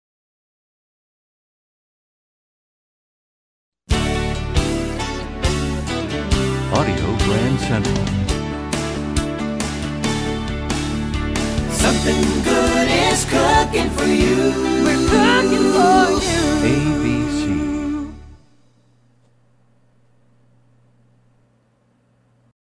MCM Category: Ad Jingles